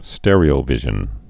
(stĕrē-ō-vĭzhən, stîr-)